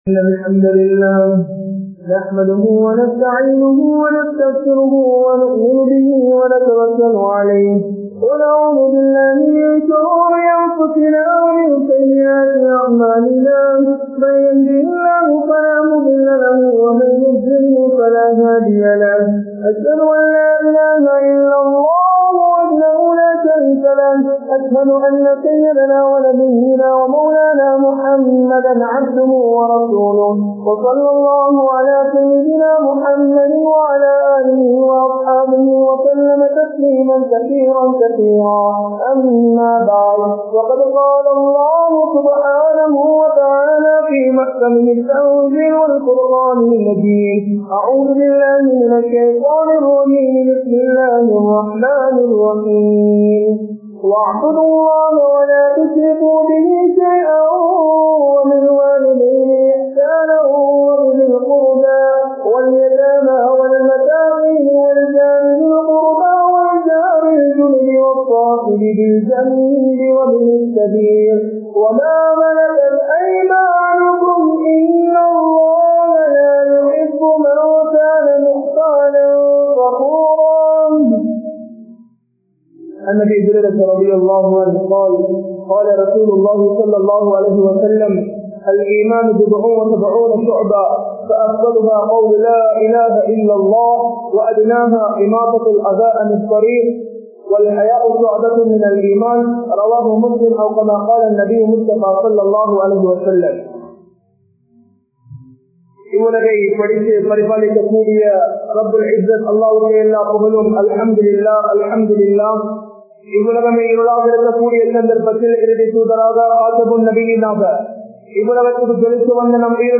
Manitha Neayam (மனிதநேயம்) | Audio Bayans | All Ceylon Muslim Youth Community | Addalaichenai